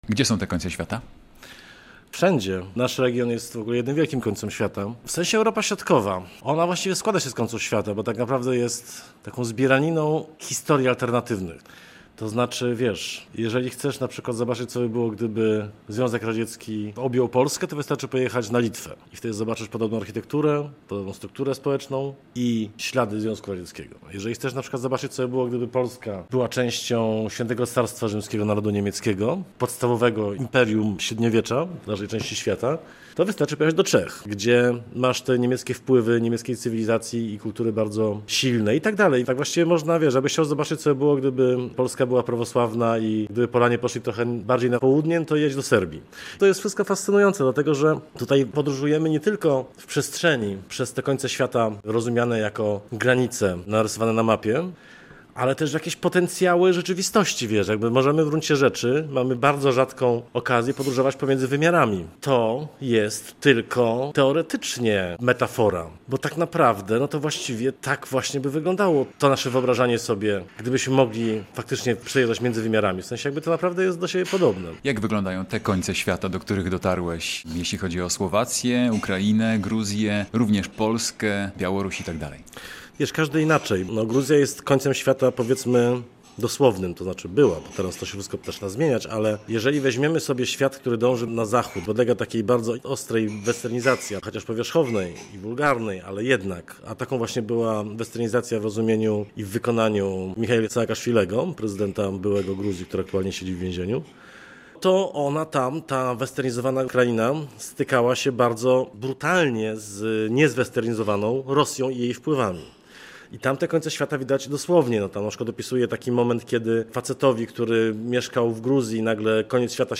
Z Ziemowitem Szczerkiem rozmawia